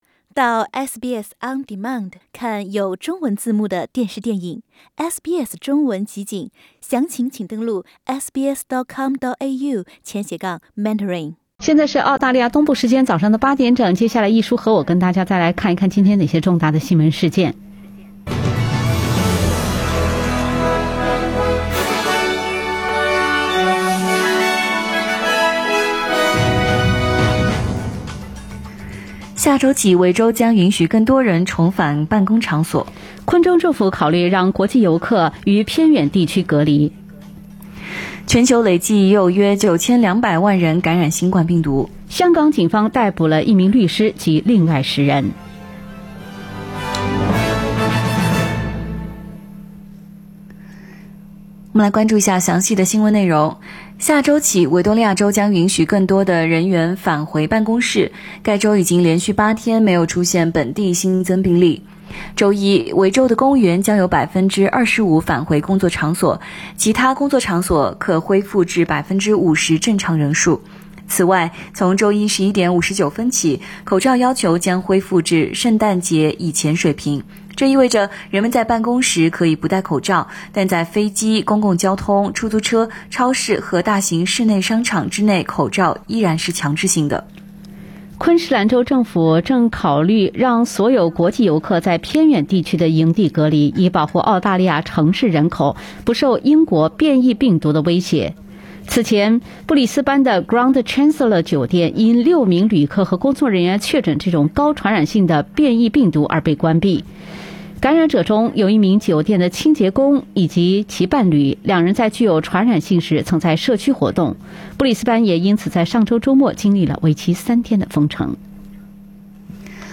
SBS早新聞（1月15日）